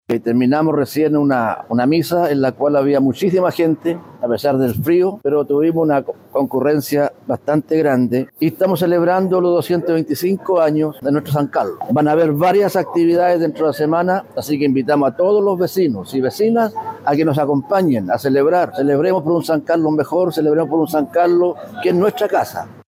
Cesar-Ortiz-concejal.mp3